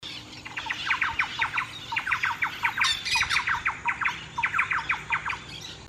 Ratona Grande (Campylorhynchus turdinus)
Nombre en inglés: Thrush-like Wren
Fase de la vida: Adulto
Condición: Silvestre
Certeza: Observada, Vocalización Grabada